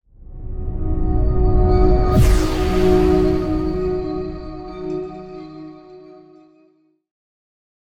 moonbeam-outro-v1-002.ogg